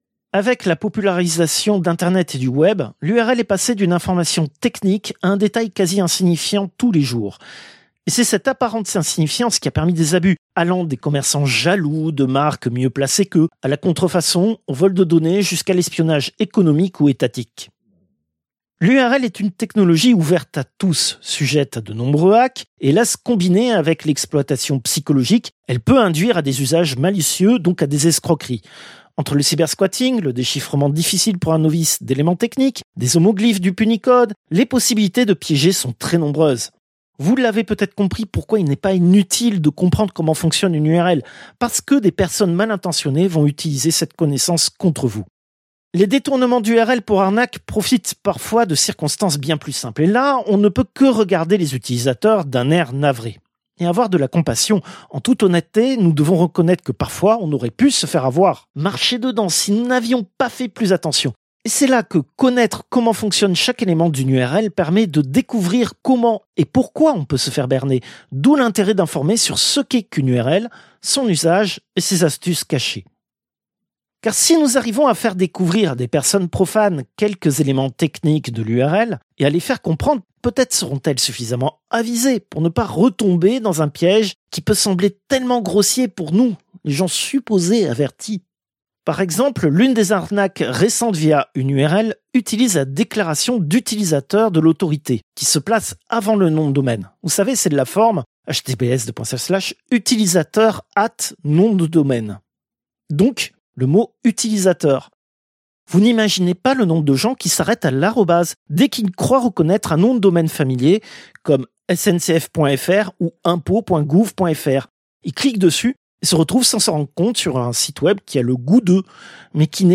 Extrait de l'émission CPU release Ex0226 : L'adresse était trop longue.